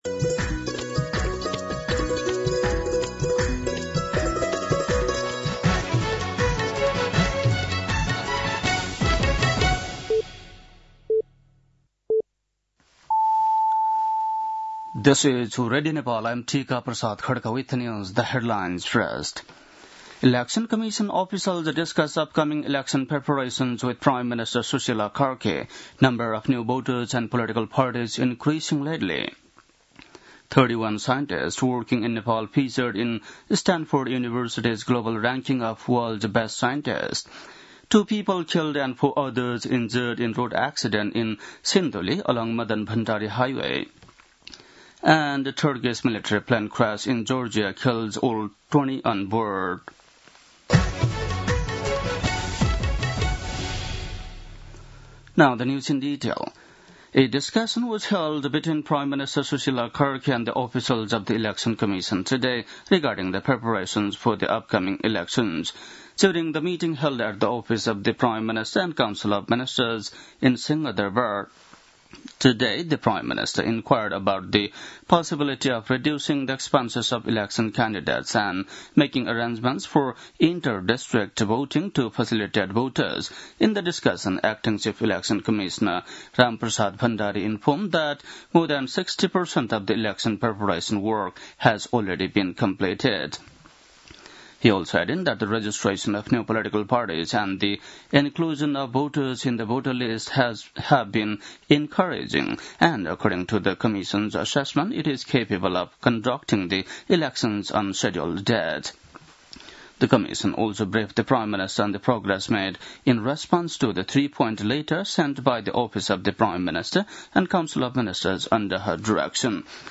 बेलुकी ८ बजेको अङ्ग्रेजी समाचार : २६ कार्तिक , २०८२
8-pm-news-7-26.mp3